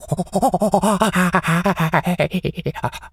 pgs/Assets/Audio/Animal_Impersonations/monkey_chatter_06.wav at master
monkey_chatter_06.wav